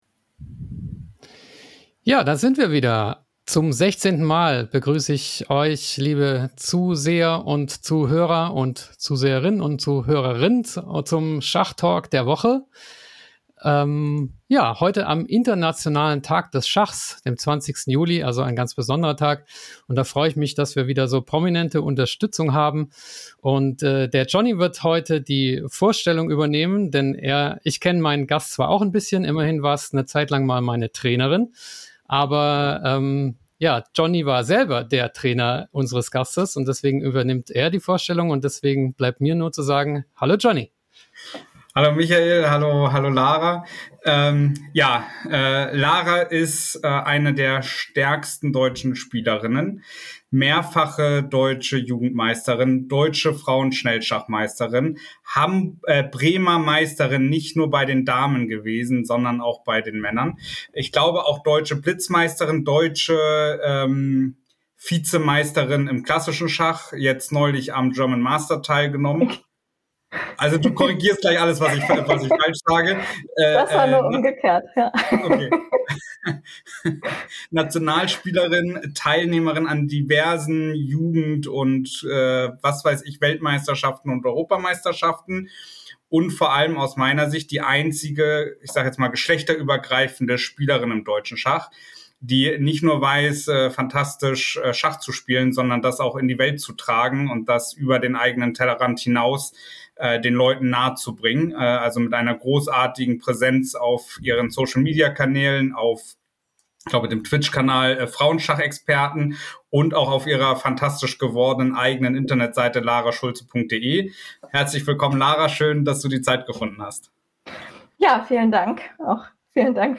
Der Schachtalk am Sonntag, immer live um 20:15 Uhr auf YouTube, LinkedIn, Instagram, Facebook und Twitch.